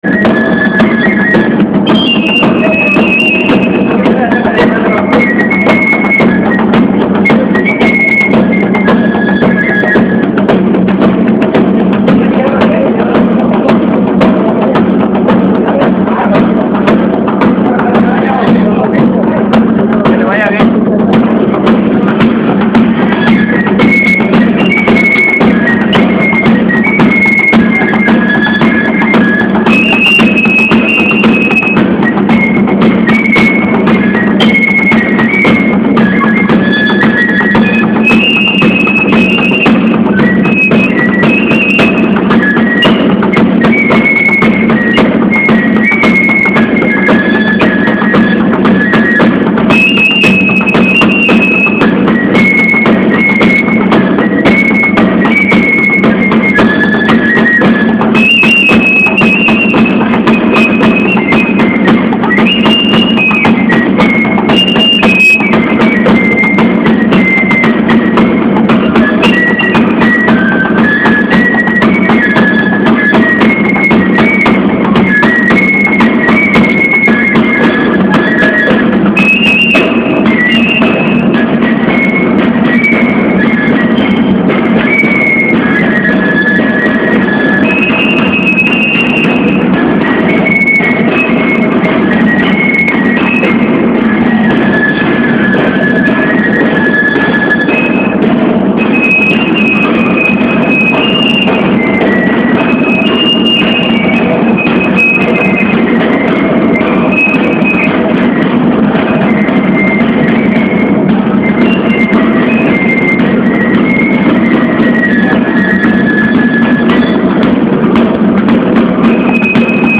Tambor y carrizo en el Museo Zoque de Copoya
Cada que tengo oportunidad de escuchar estos sonidos ancestrales, me brotan las ganas de danzar al compás de la tradición.
Equipo: Grabadora Telefono Samsung S5230